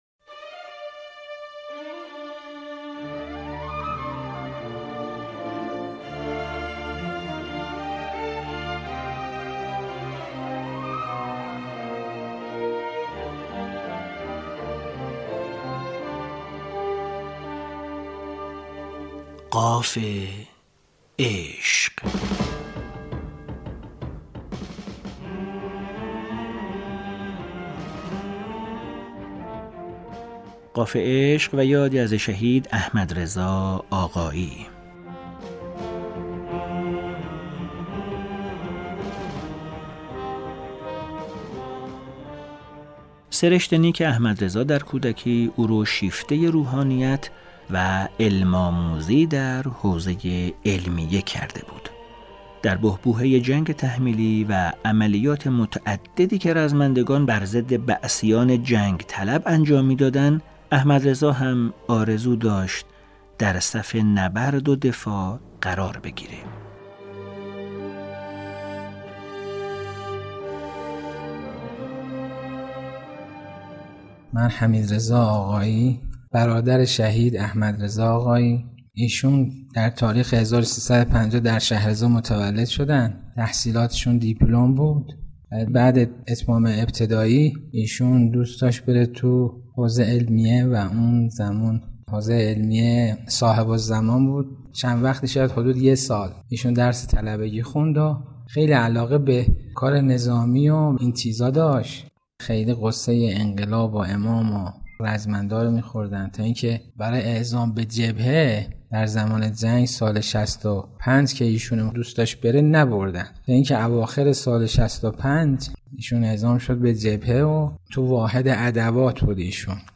منبع: برنامه رادیویی قاف عشق